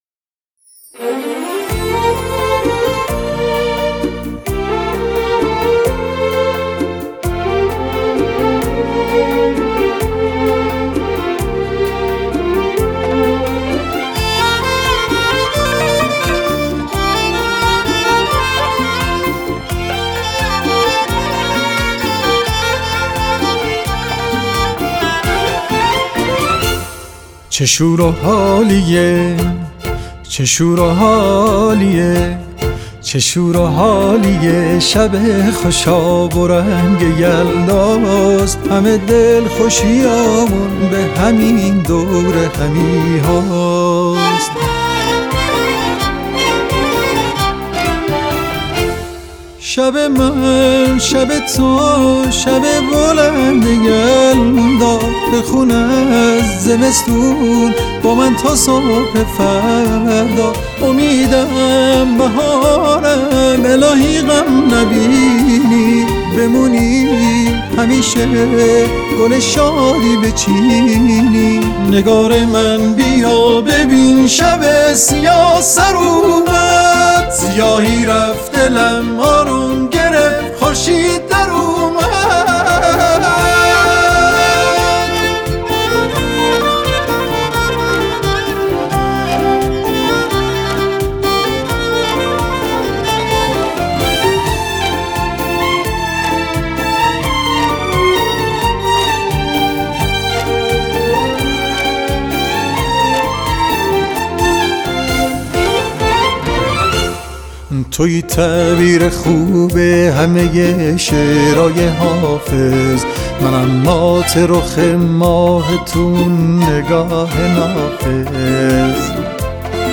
با سبک و سیاق موسیقی ایرانی
خواننده سنتی خوان و ایرانی آهنگ سنتی-کلاسیک